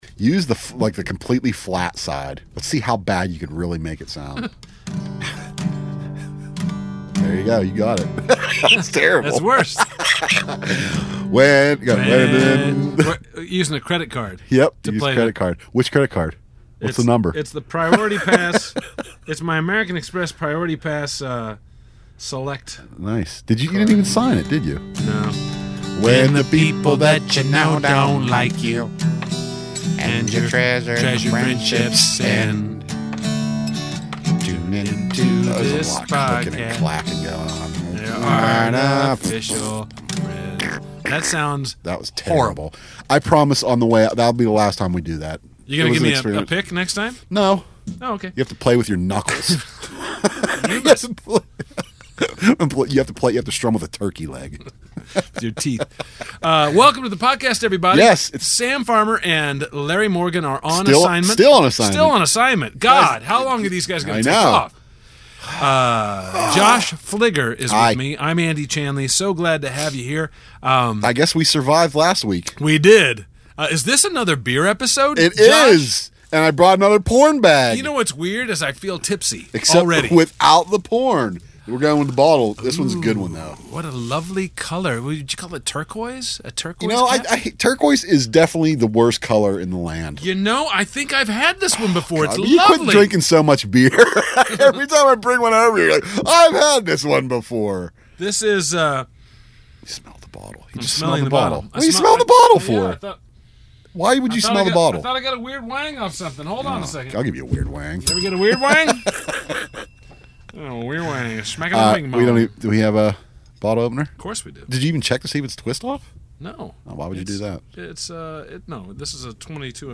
Oh, and we hear your YAF Line calls.